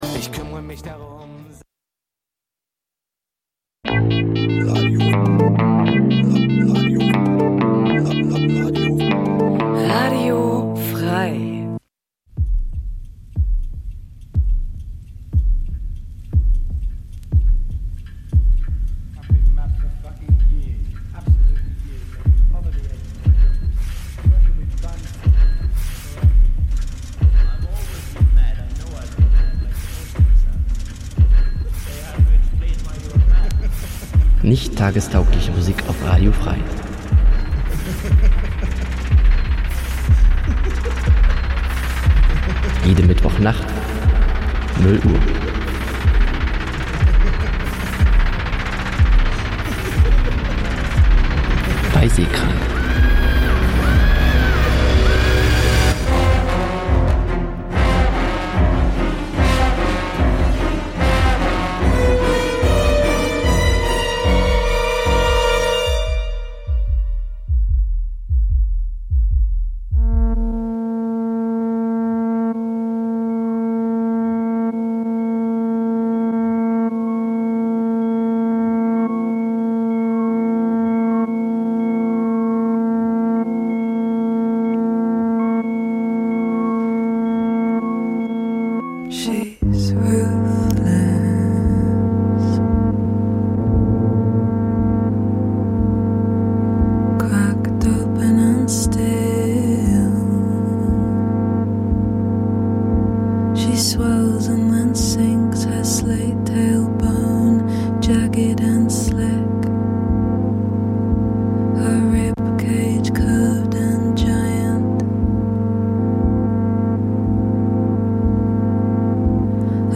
SEHKRANK pr�sentiert Musik auch jenseits dieser alternativen H�rgewohnheiten, mal als Album, mal als Mix.